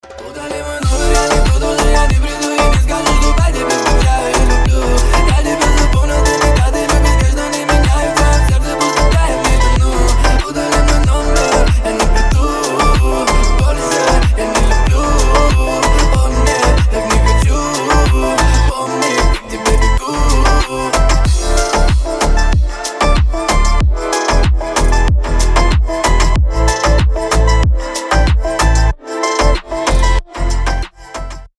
• Качество: 320, Stereo
ремиксы